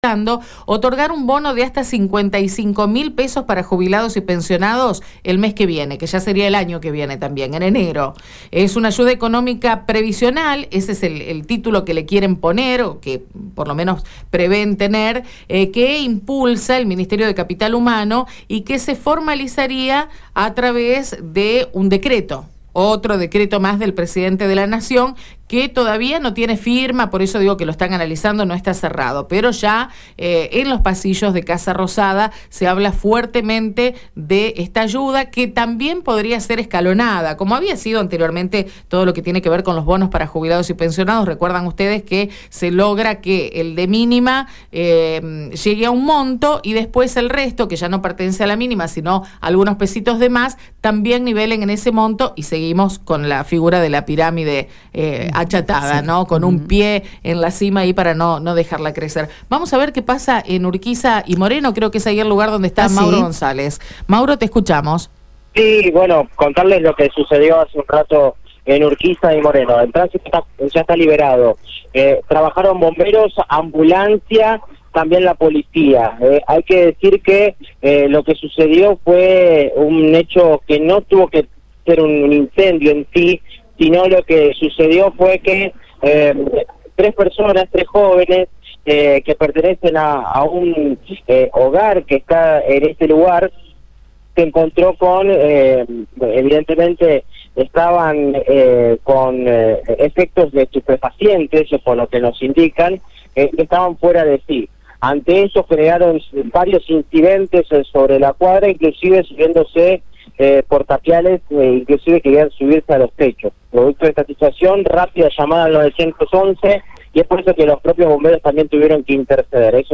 Según informó el móvil de Radio EME en el lugar, las chicas se habían ausentado por una semana y volvieron para buscar las pertenencias que habían dejado.